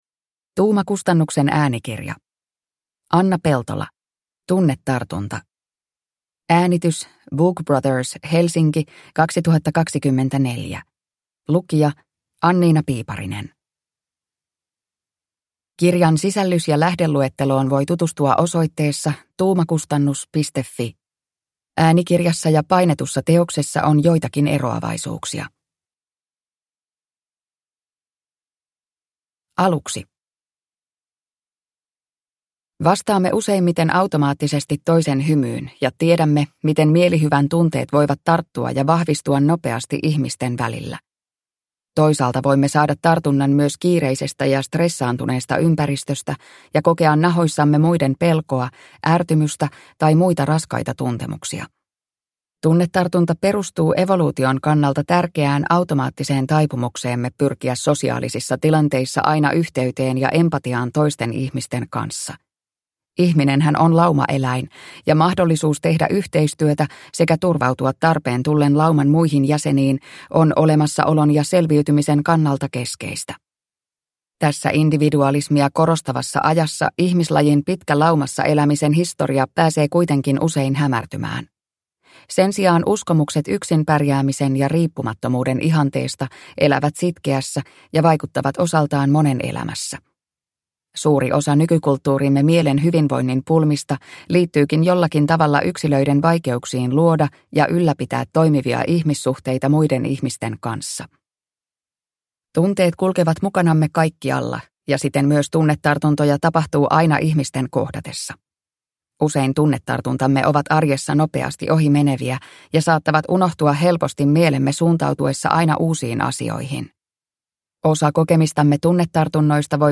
Tunnetartunta (ljudbok) av Anna Peltola